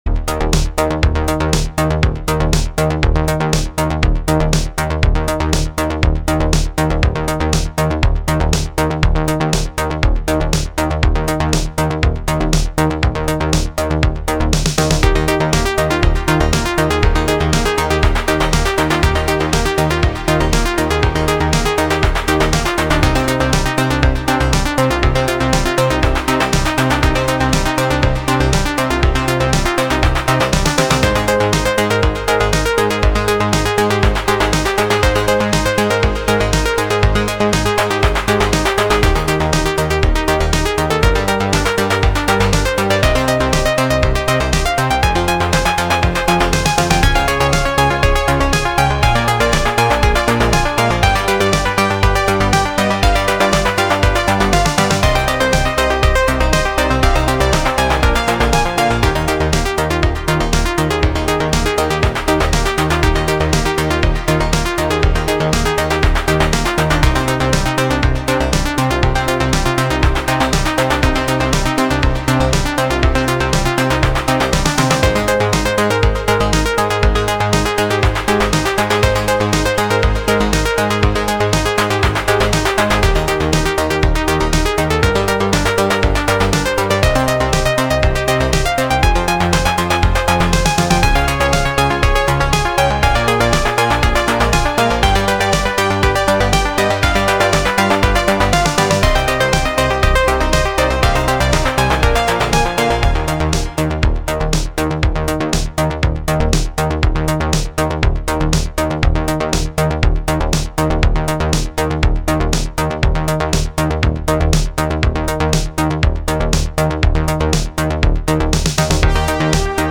lo_fi_electronic_cosmic_voyager.mp3